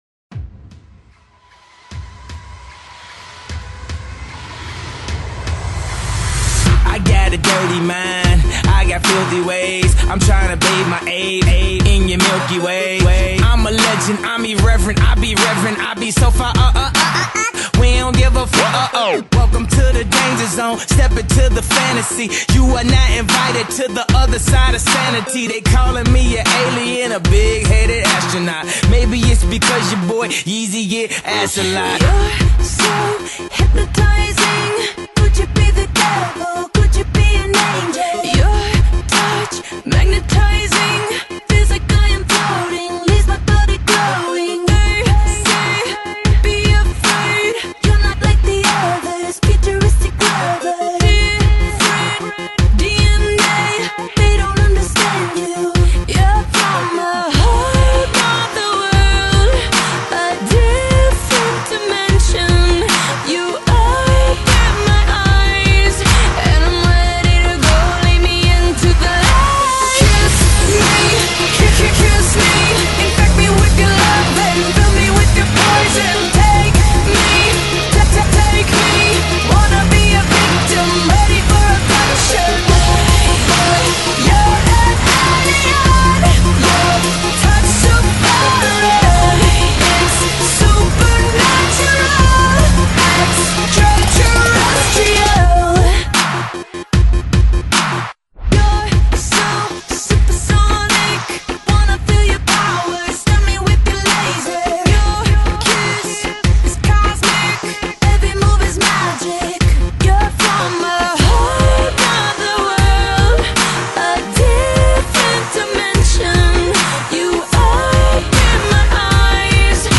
Rap (Hip-Hop) , Hardcore